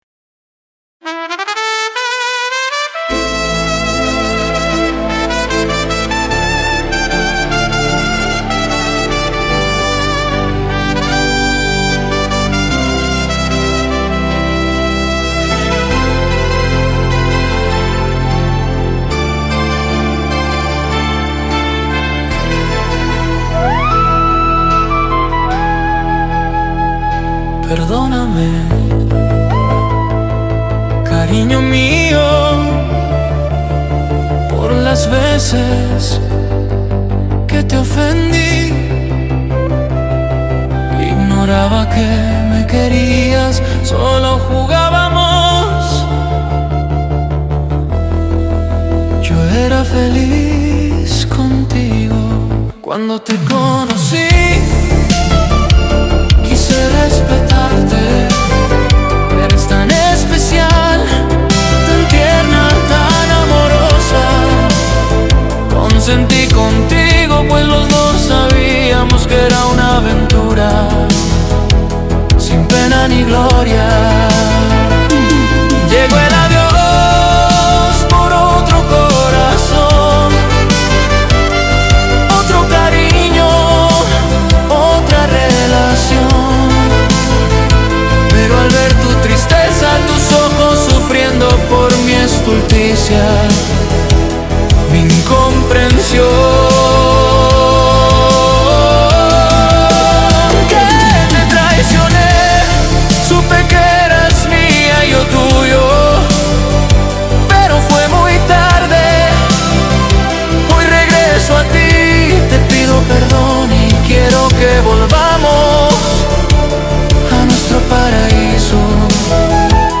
Folklore / Traditional
Genre Tradicional folklore